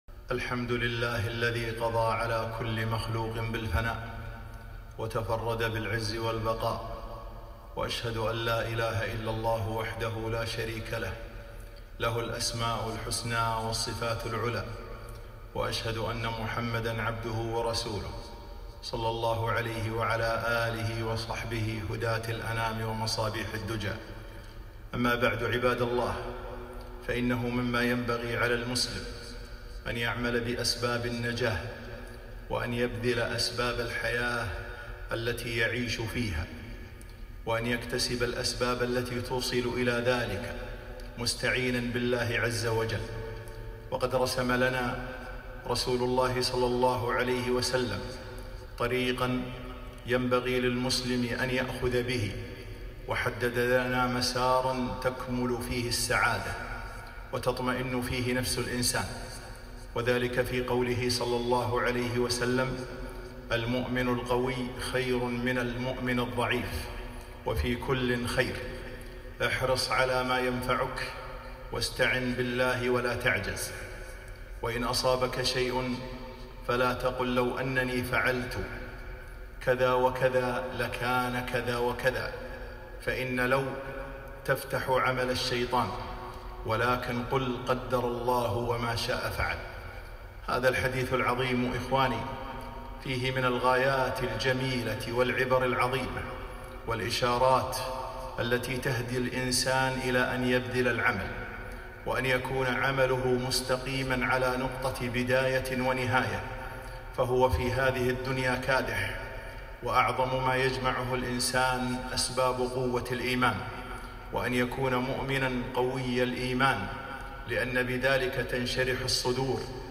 خطبة - الثقة في الله